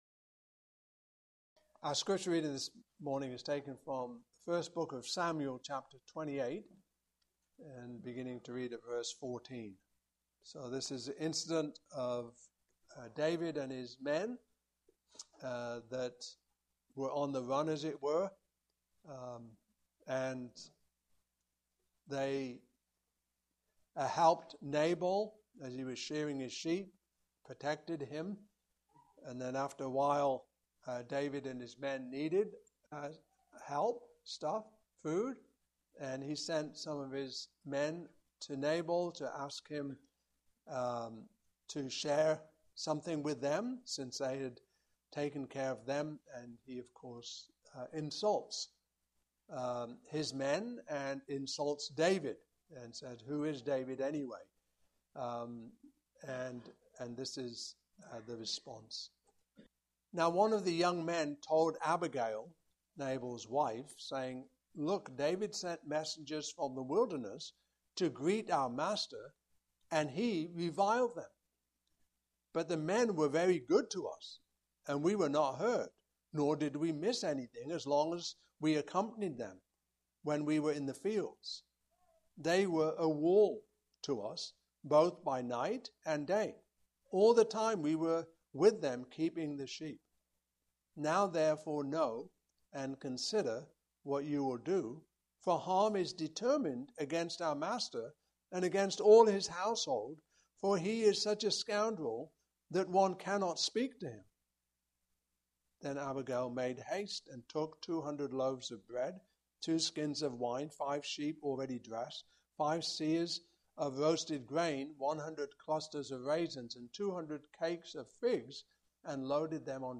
Fruit of the Spirit Passage: I Samuel 25:14-38 Service Type: Morning Service Topics